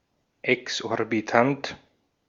Ääntäminen
IPA: /ɛksʔɔʁbiˈtant/